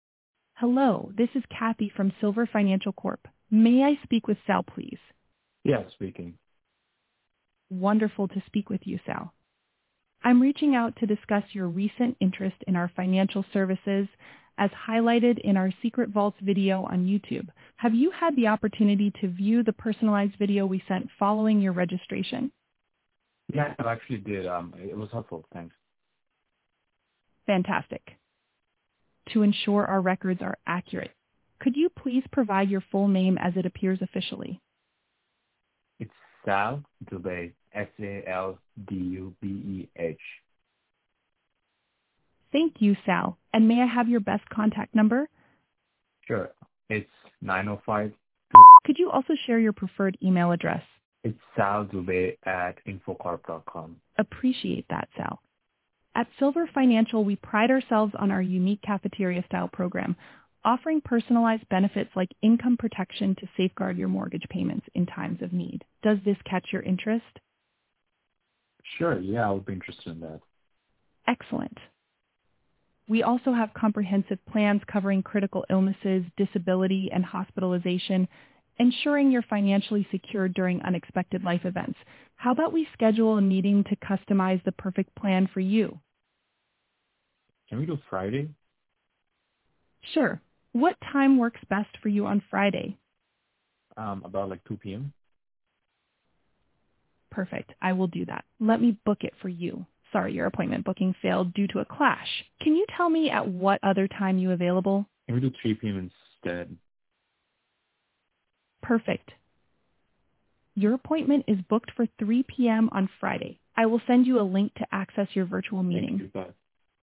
Experience Ultra-Realistic AI Voice Agent
Real Estate call AI Agent